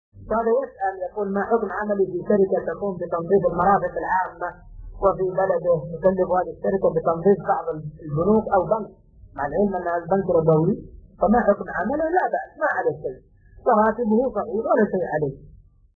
السؤال : ما حكم عملي في شركة تقوم بتنظيف المرافق العامة وفي بلده تجب هذه الشركة بتنظيف بعض البنوك أو البنك مع العلم أن هذا البنك ربوي فما حكم عمله ؟الجواب : لابأس ماعليه شيء وراتبه صحيح ولا شيء عليه .السؤال مقتطف من شرح كتاب الصيام من زاد المستقنع .